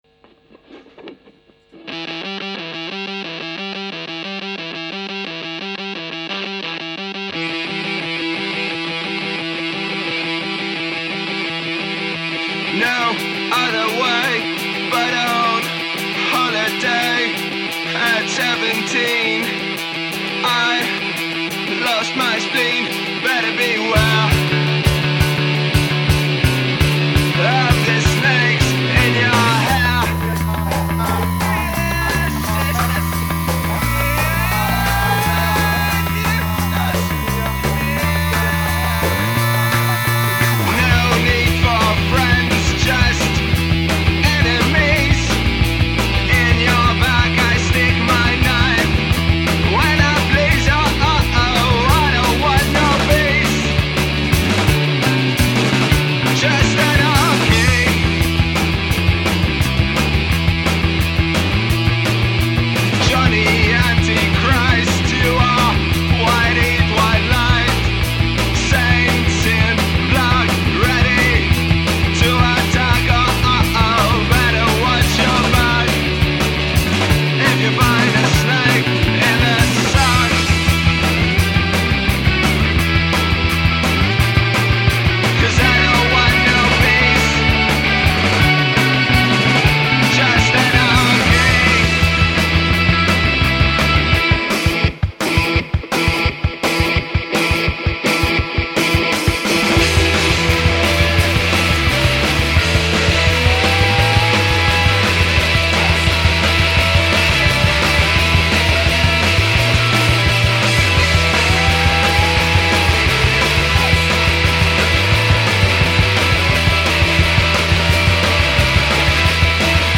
went into the studio
of angry and scorching punk nuggets